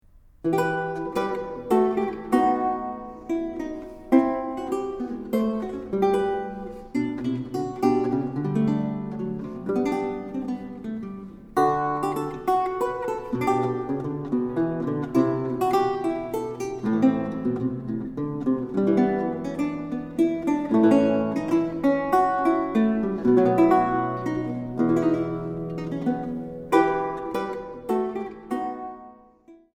Italienische Lautenmusik des Barock
Laute
Orgelpositiv